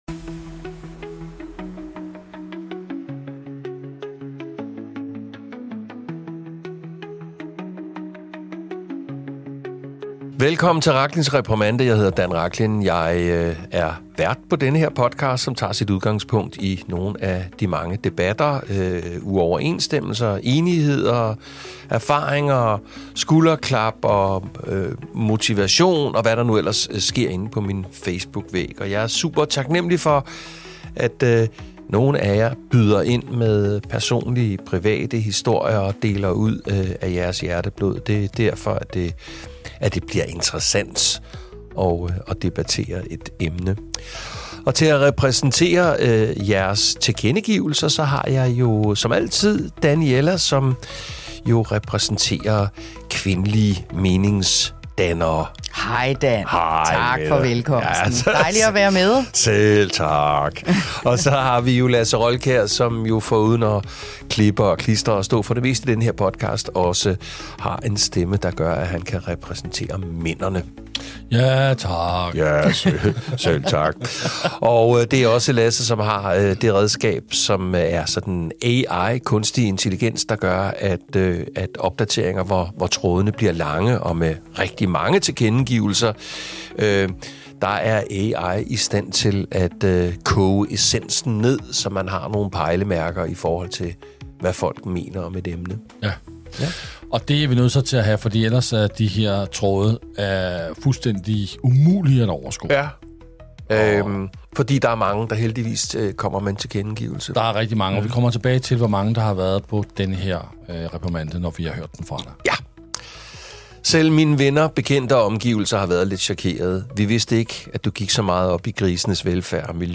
En episode med holdninger, clash, refleksion og en debat, der rammer langt bredere end bare spørgsmålet om grise.